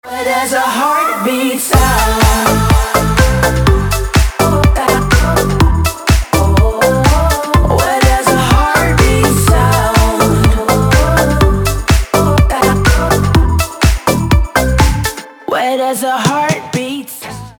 • Качество: 320, Stereo
женский вокал
dance
club